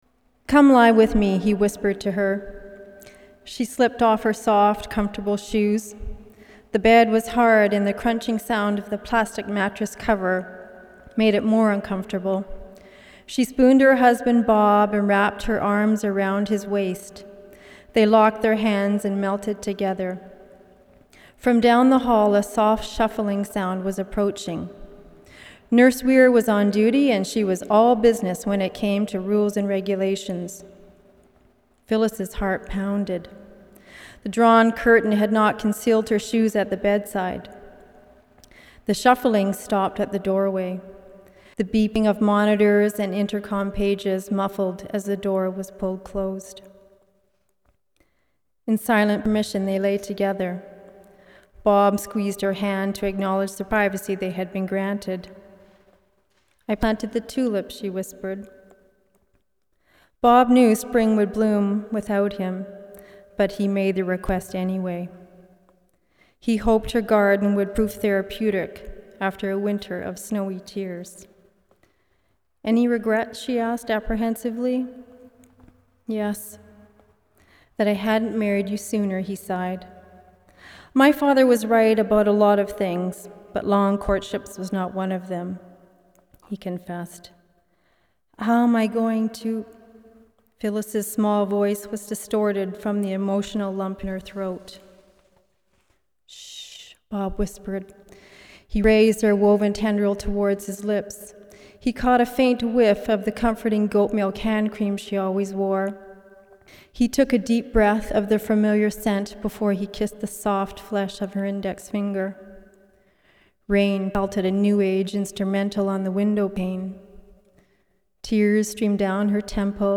Readings from an Evening of Readings by Writers